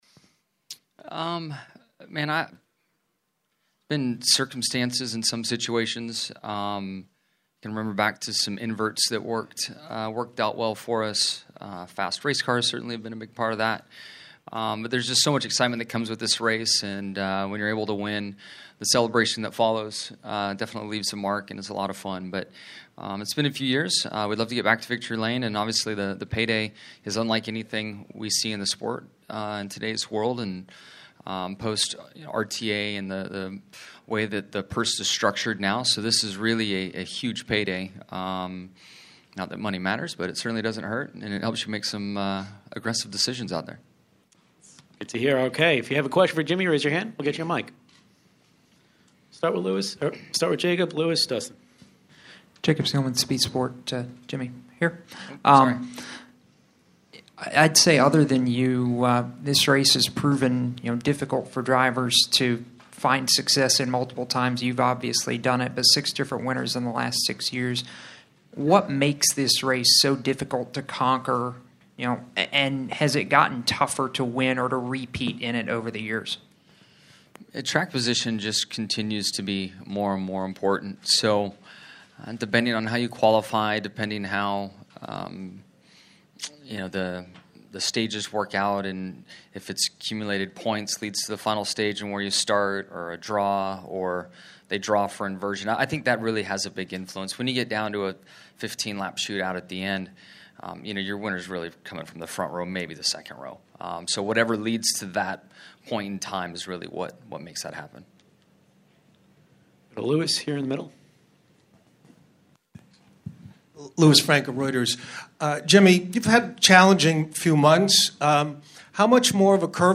DAYTONA BEACH, Fla. – Dressed in shorts, a polo and his sponsor Ally’s ballcap, Jimmie Johnson looked comfortable and right at home Friday afternoon meeting with members of the media before qualifying at Charlotte Motor Speedway.